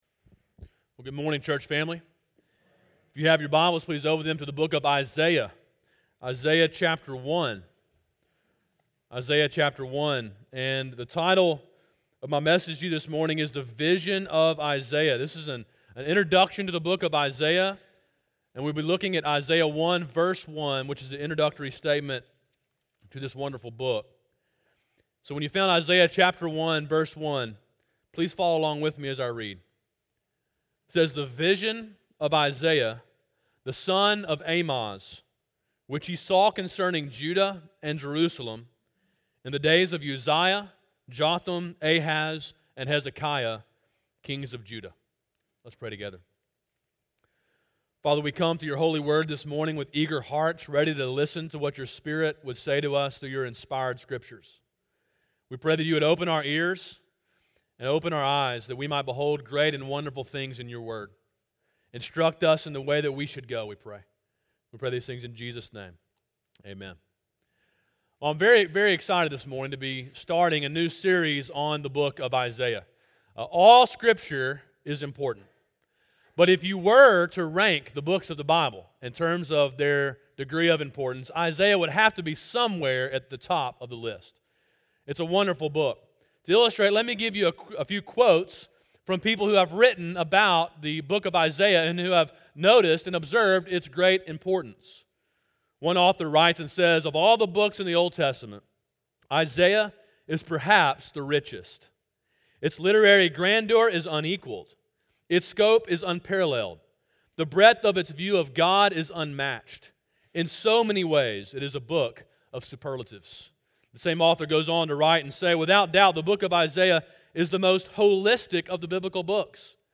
Sermon: “The Vision of Isaiah” (Isaiah 1:1) – Calvary Baptist Church